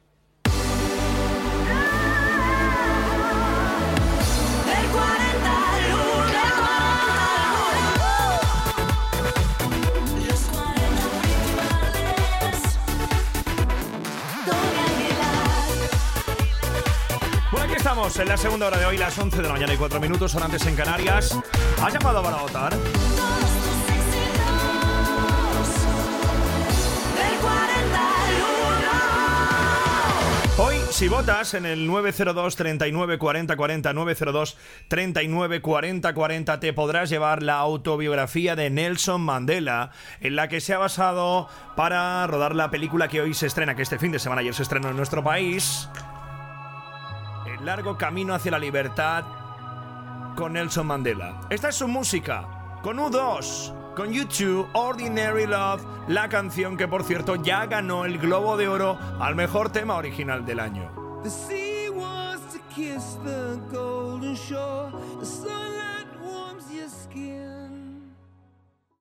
Careta del programa, hora, telèfon, premi, tema de la pel·lícula "El largo camino hacia la libertad".
Musical
FM